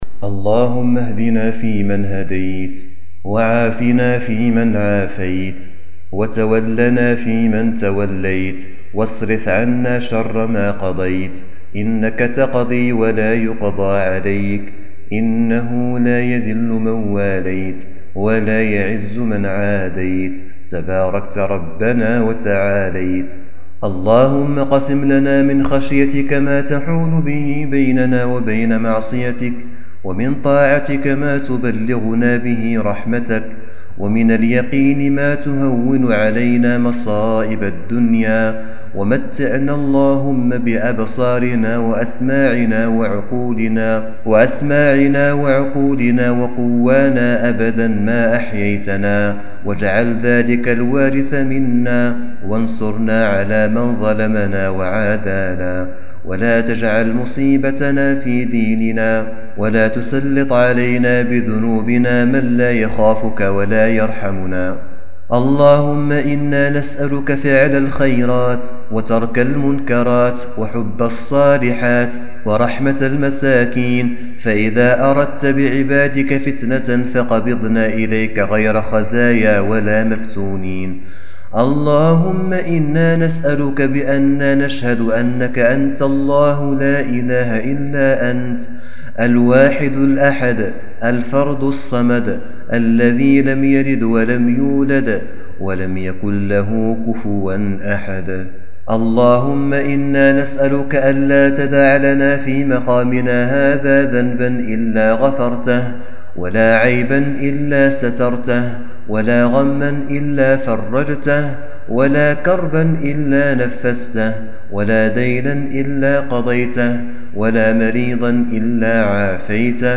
دعاء خاشع من صلاة التراويح
تسجيل لدعاء خاشع ومؤثر
من صلاة التراويح.